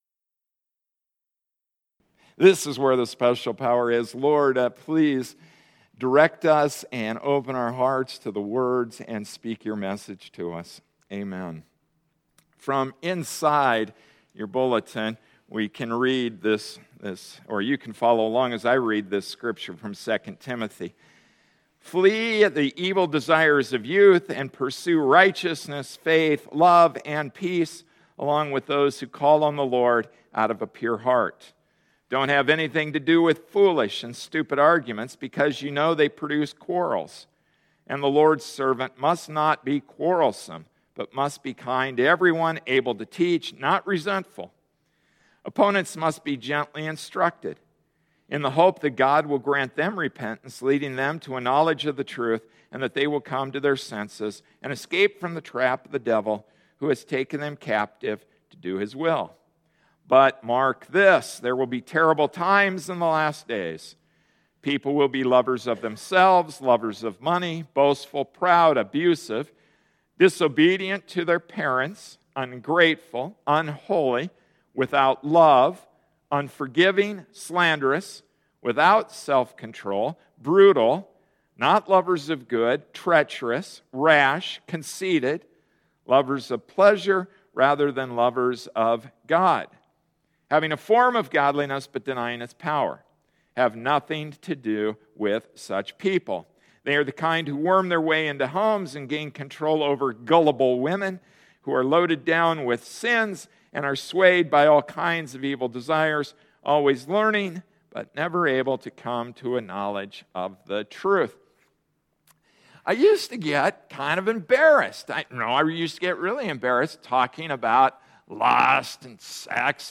June 22, 2014 Lust to Self-Control Passage: 2 Timothy 2:22--3:7 Service Type: Sunday Morning Service “Lust to Self-control” Introduction: Who’s guilty?